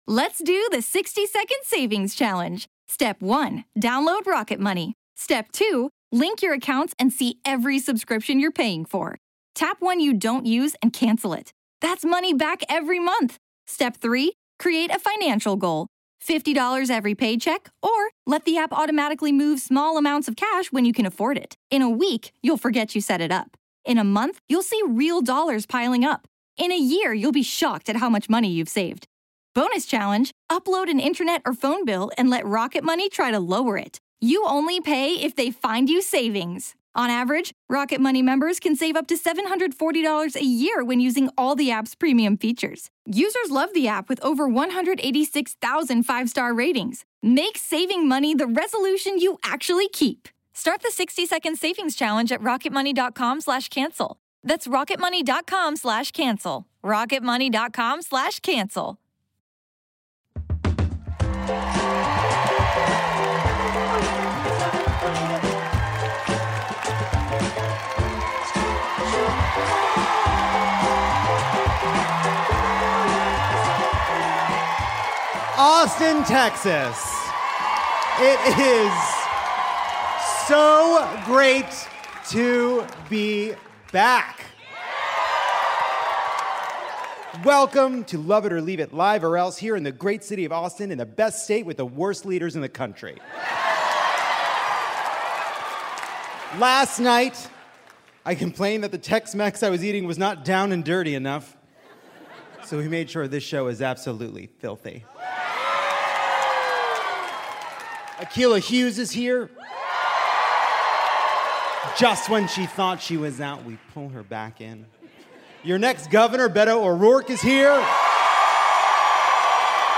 Lovett or Leave It pulls on its cowboy boots and takes its Lactaid pills for a queso-filled quorum in Austin, Texas. Beto O’Rourke takes on Greg Abbott and plays Queen for a Day.